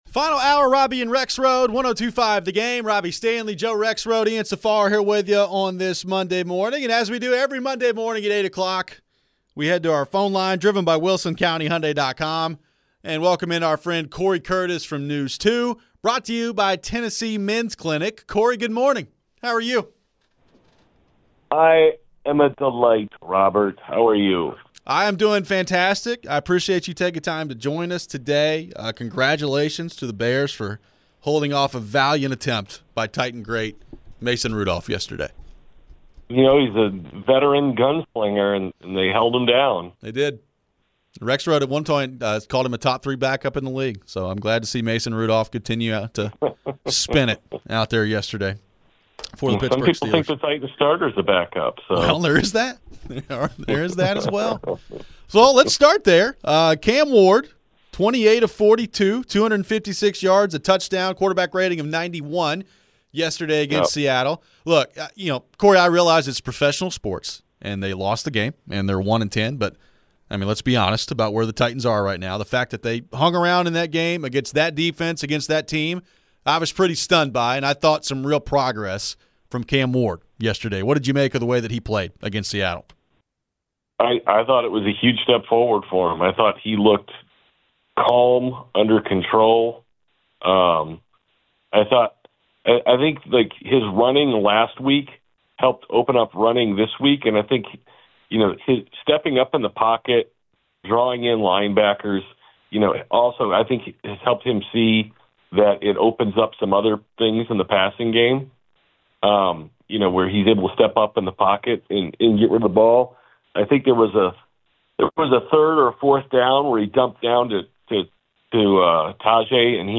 How encouraged should fans be about the performance from Cam Ward and the offense? We get back to the phones.
We wrap up the show with your phones and some thoughts on Vandy vs Vols this upcoming weekend.